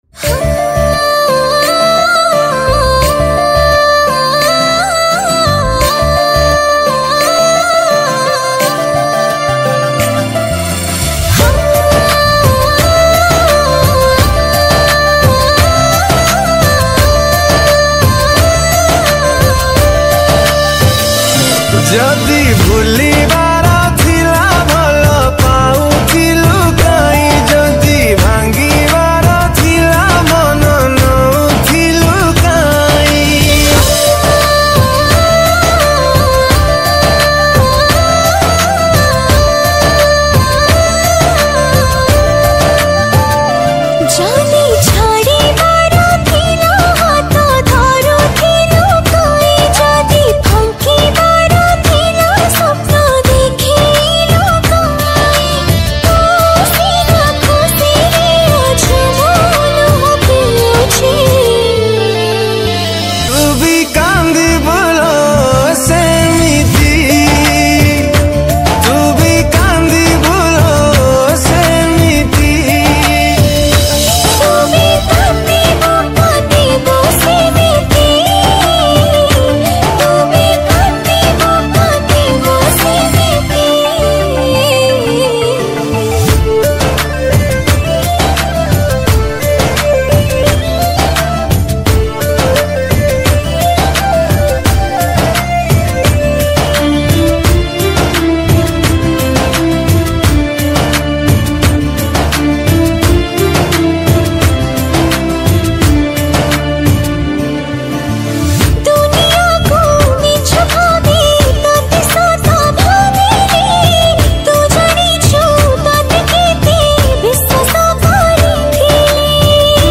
Duet